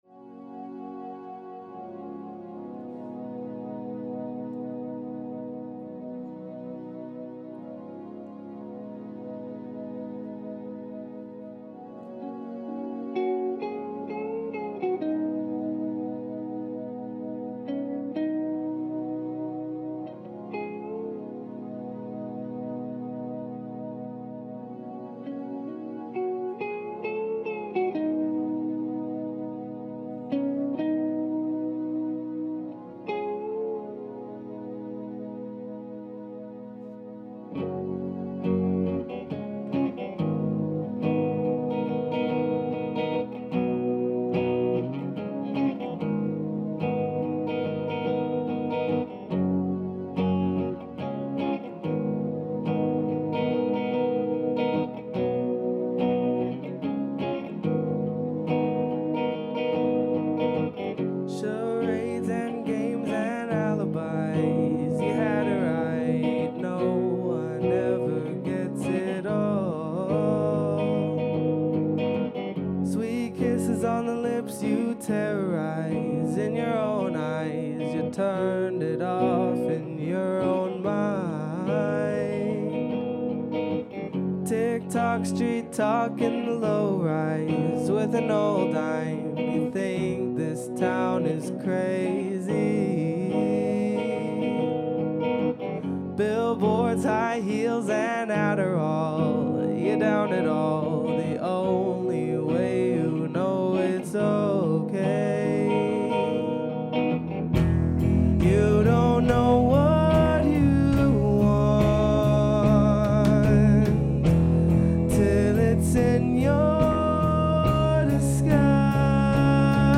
performed live
cello